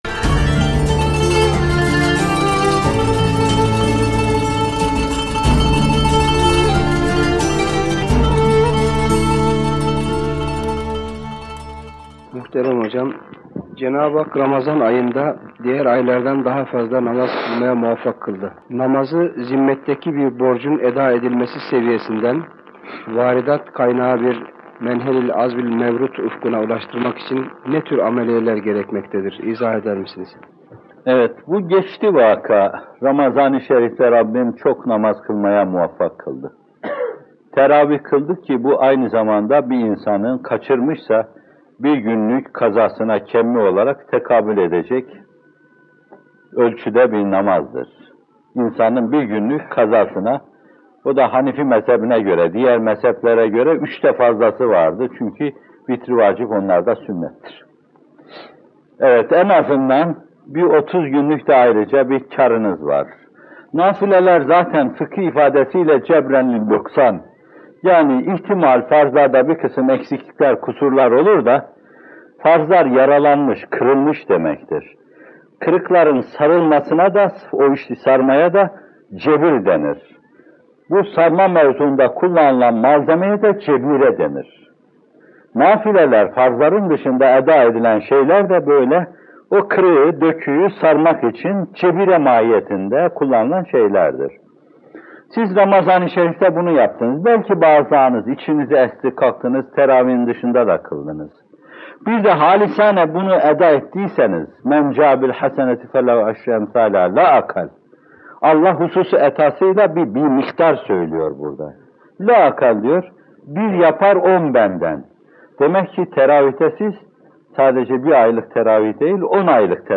Sahabe İzinde Bir Ramazan İçin - Fethullah Gülen Hocaefendi'nin Sohbetleri